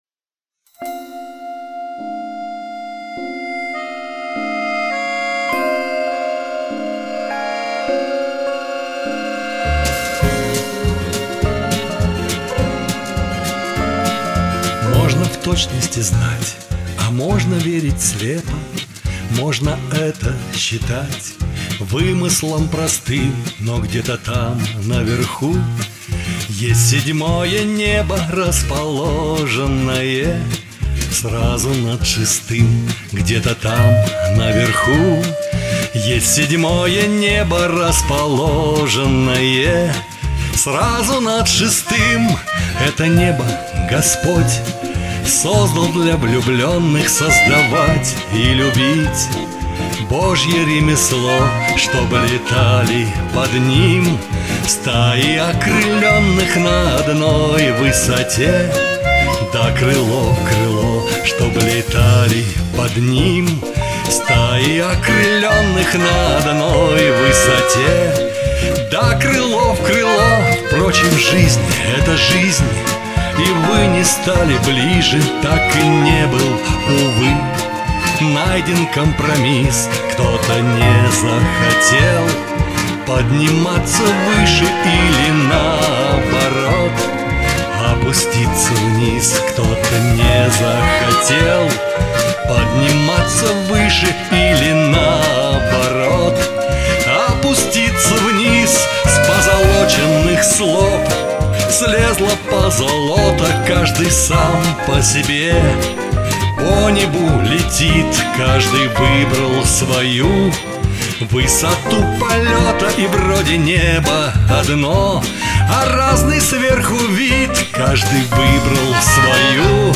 ты тут мягкой музыкальностью посыла